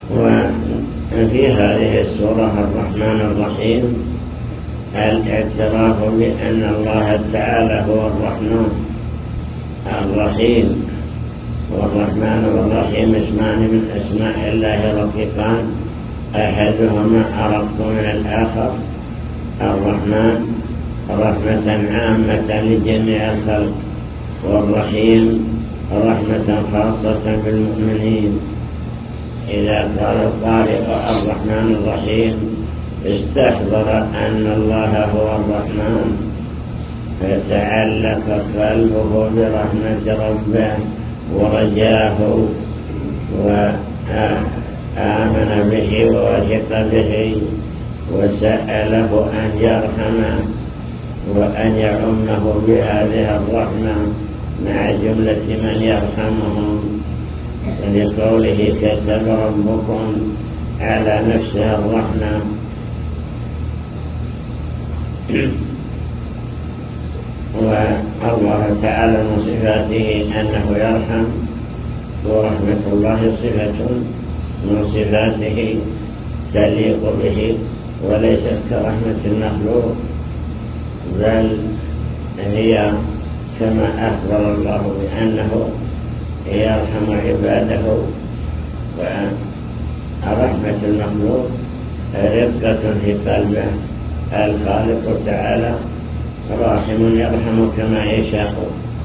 المكتبة الصوتية  تسجيلات - لقاءات  حول أركان الصلاة (لقاء مفتوح) من أركان الصلاة: قراءة الفاتحة